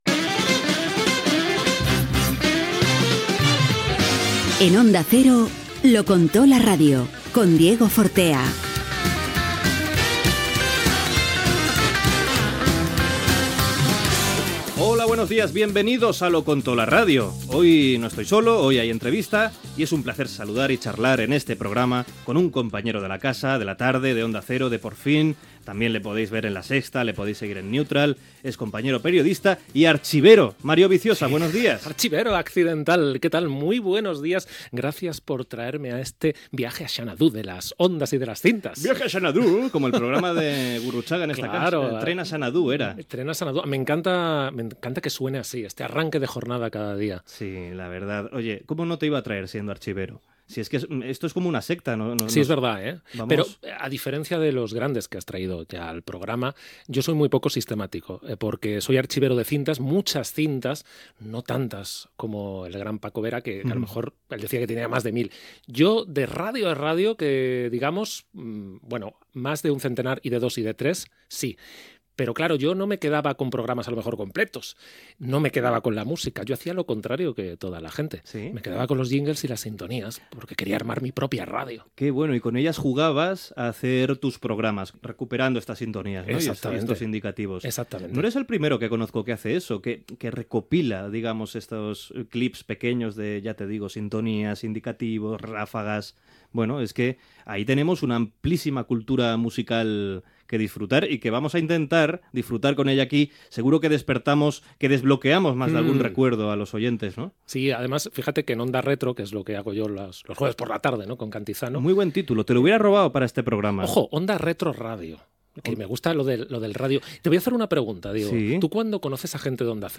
Careta del programa, presentació, entrevista
Gènere radiofònic Entreteniment